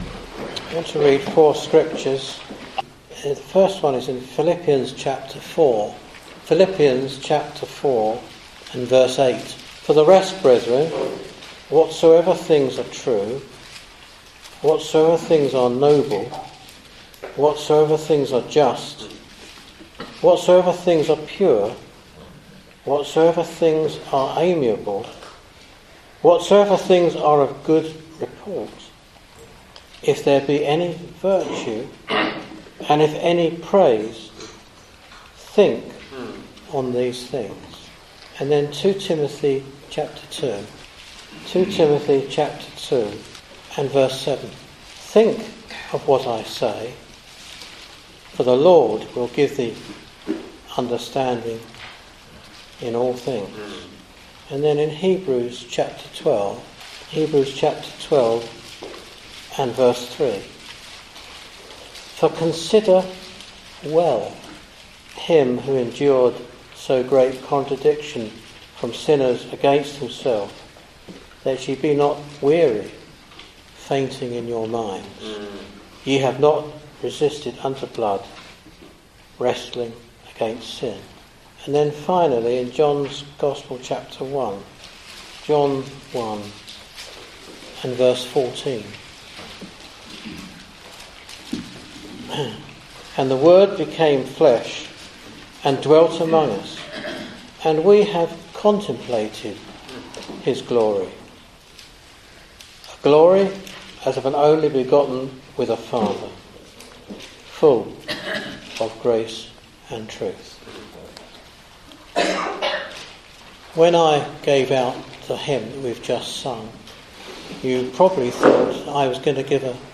Bible Teaching (Addresses)